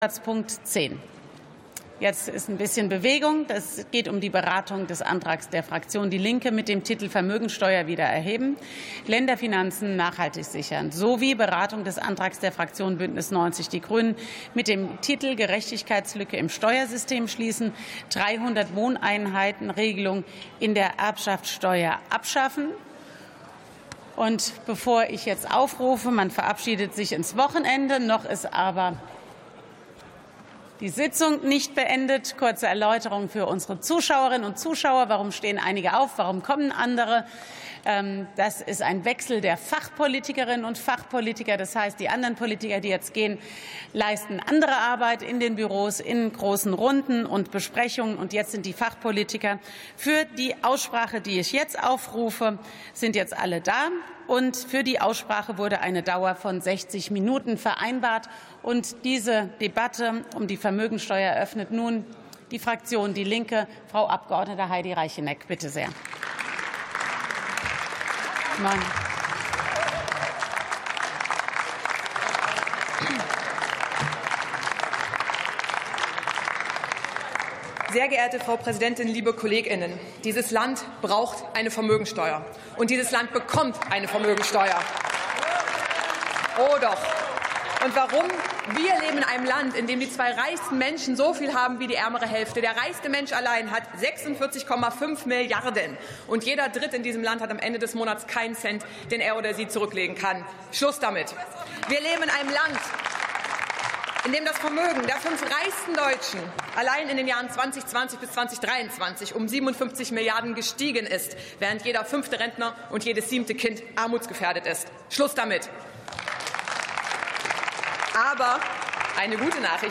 63. Sitzung vom 06.03.2026. TOP 20, ZP 10: Vermögensteuer ~ Plenarsitzungen - Audio Podcasts